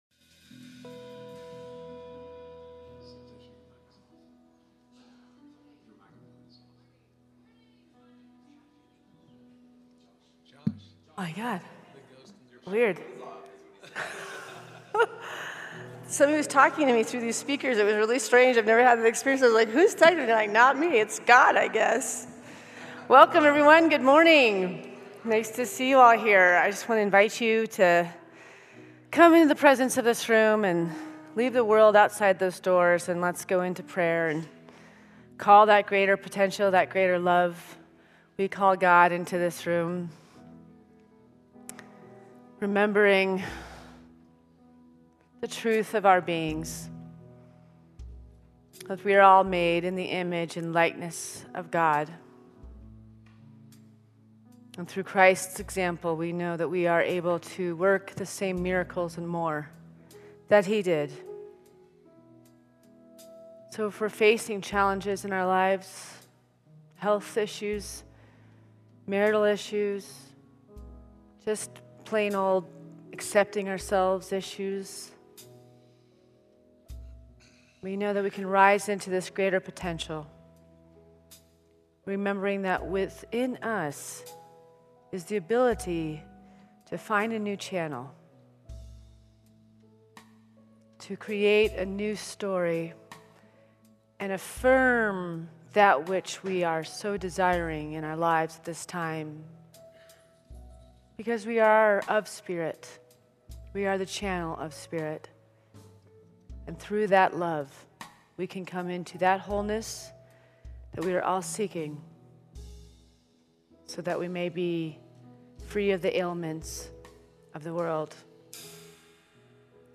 A Minister’s Panel discussing “How Do You Prove God?”
Tagged with Ministers Panel